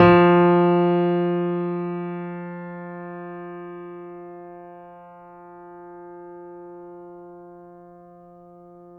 pianoSounds